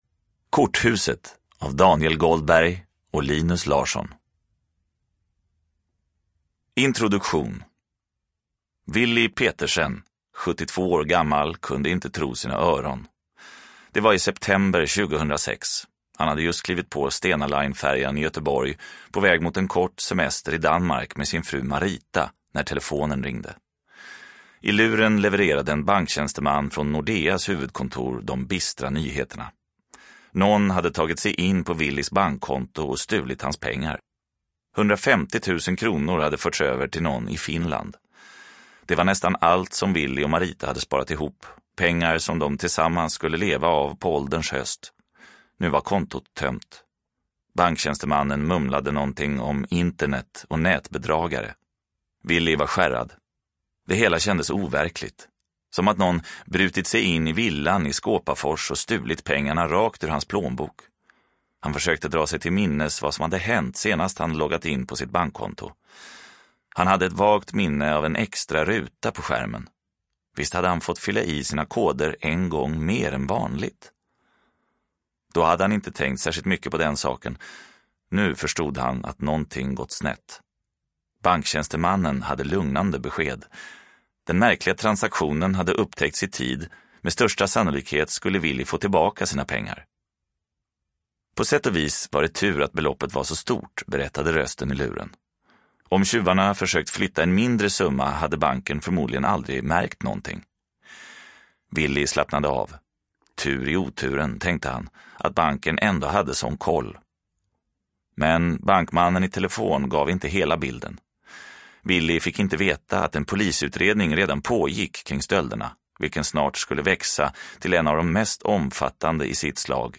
Korthuset – Ljudbok – Laddas ner